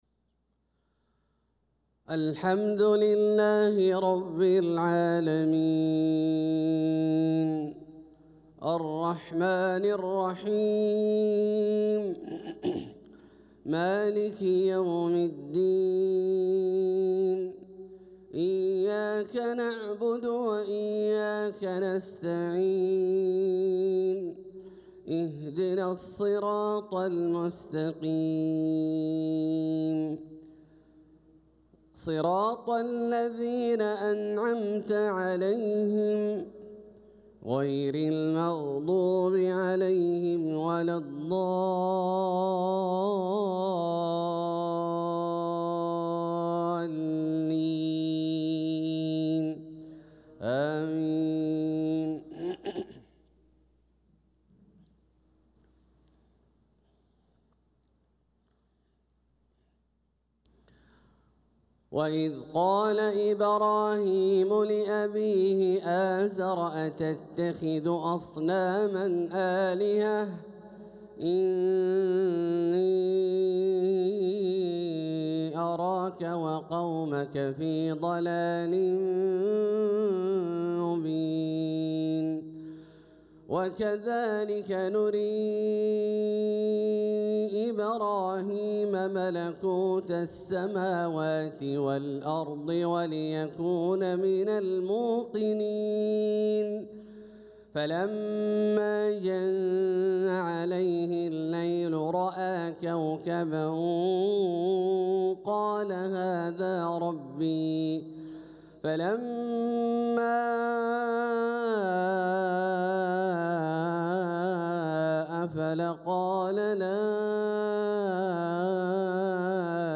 صلاة الفجر للقارئ عبدالله الجهني 8 ربيع الأول 1446 هـ
تِلَاوَات الْحَرَمَيْن .